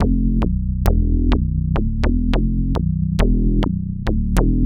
Bass 40.wav